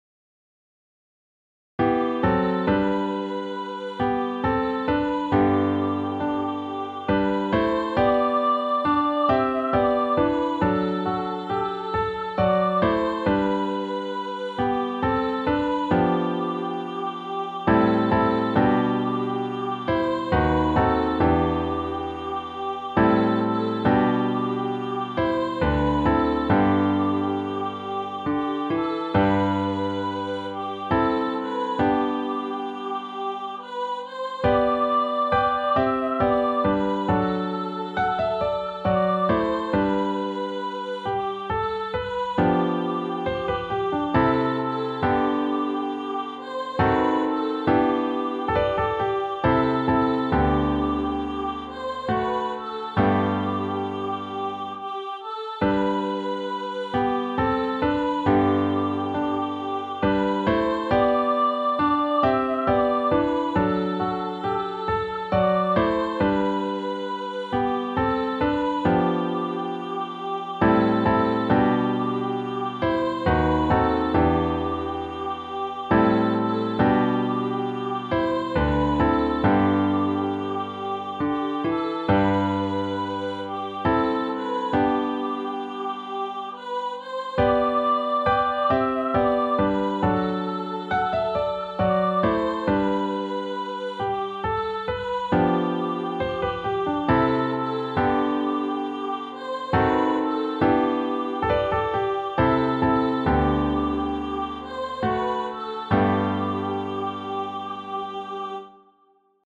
Sesotho hymn
Voice+Piano